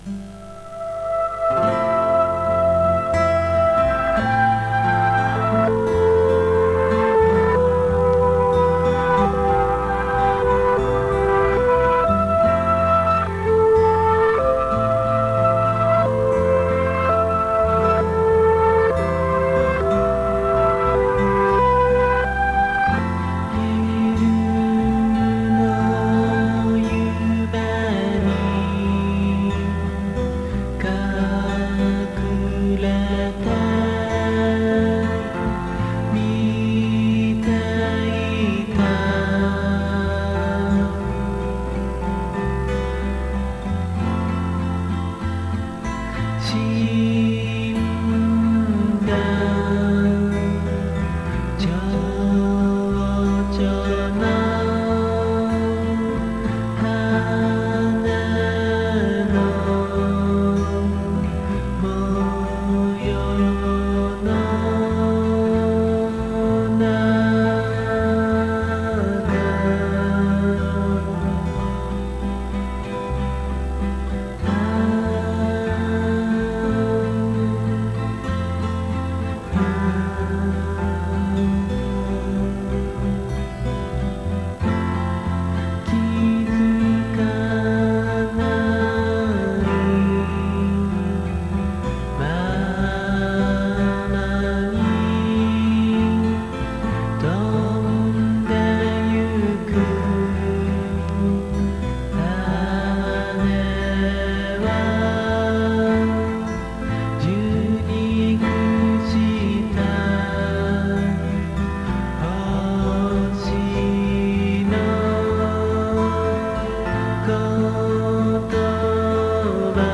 極上アシッド・フォーク作品！
※レコードの試聴はノイズが入ります。